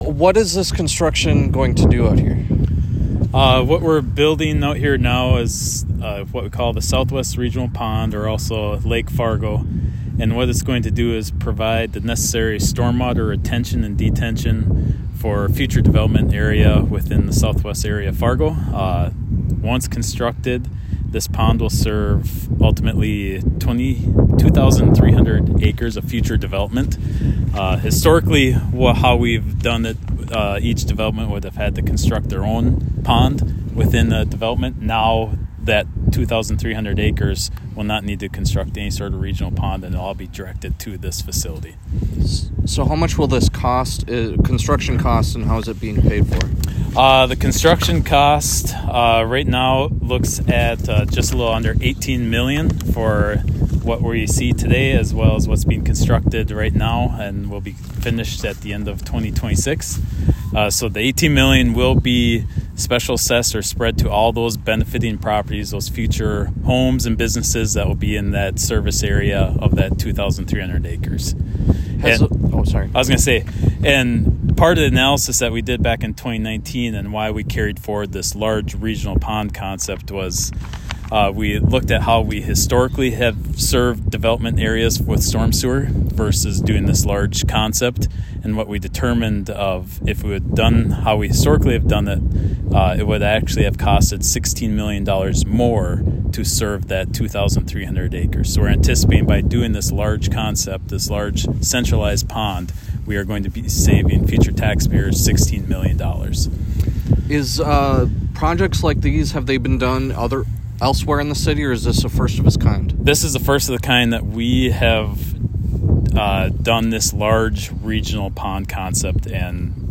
lake-fargo-interview.m4a